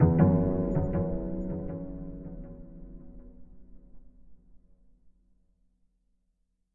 描述：G中的幽灵民族弦乐器
标签： etnic 时态 仪器 恐怖 种族 张力
声道立体声